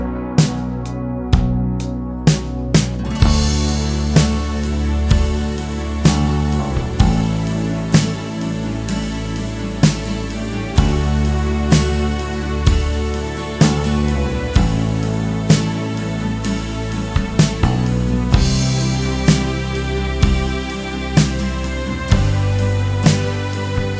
Minus All Guitars Rock 1:49 Buy £1.50